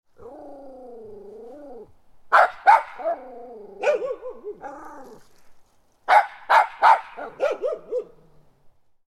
Animal Sounds / Dog Sounds / Sound Effects
Small-and-large-dog-barking-sounds.mp3